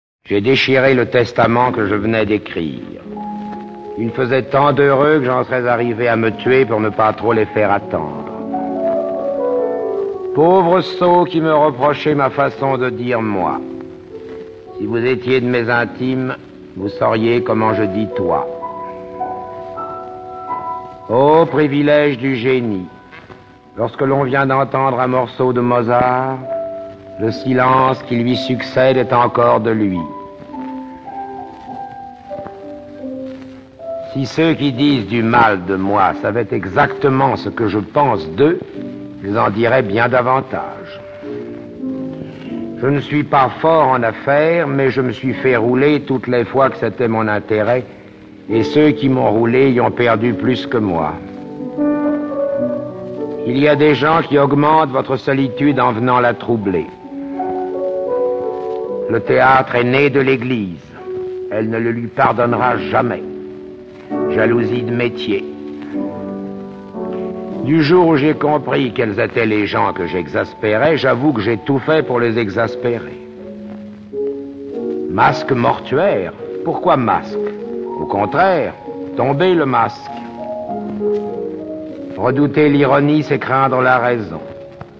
Diffusion distribution ebook et livre audio - Catalogue livres numériques
Enregistrement original (extraits)